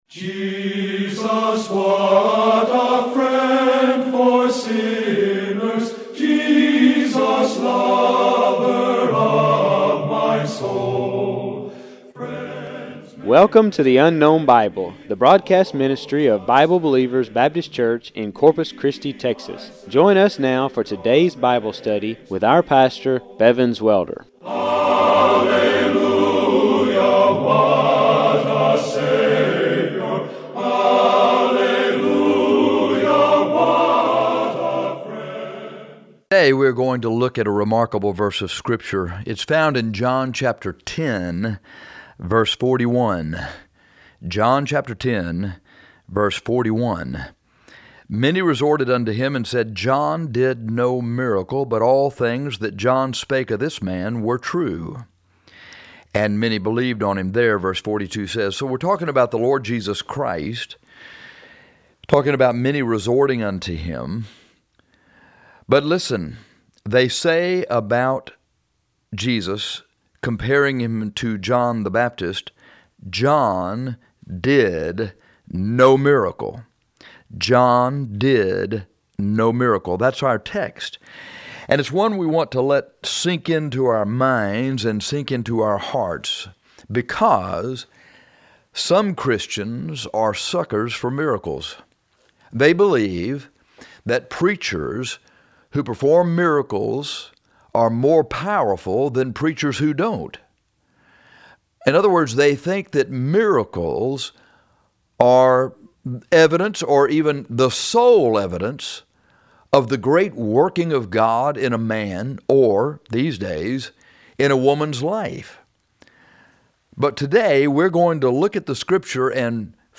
John Did No Miracle Jn 10: 41 CLICK TITLE FOR AUDIO of this sermon on miracles.